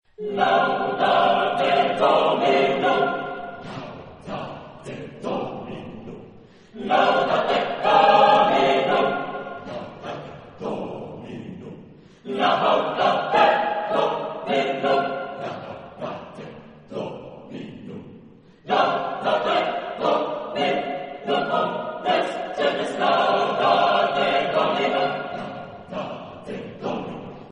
Género/Estilo/Forma: Motete ; Sagrado
Tipo de formación coral: SSATBB  (6 voces Coro mixto )
Tonalidad : libre